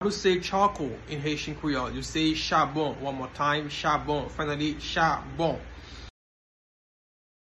Pronunciation:
Charcoal-in-Haitian-Creole-Chabon-pronunciation-by-a-Haitian-teacher.mp3